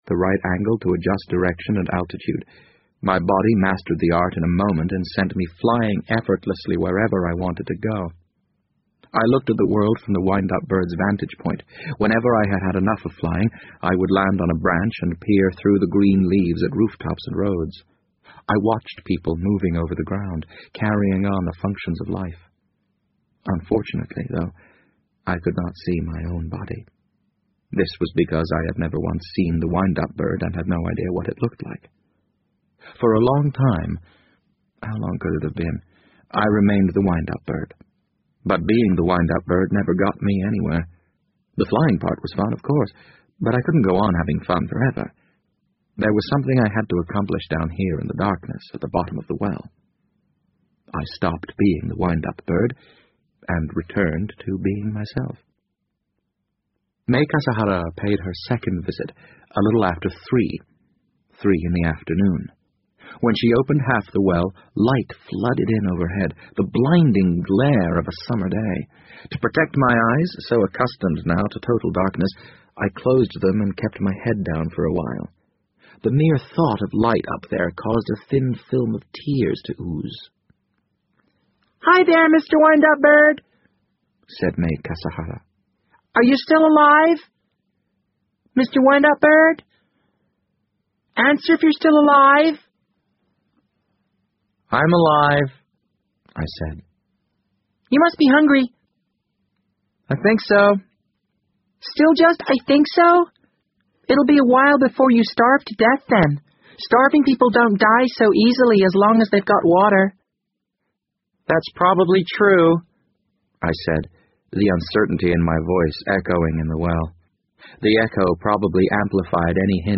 BBC英文广播剧在线听 The Wind Up Bird 007 - 6 听力文件下载—在线英语听力室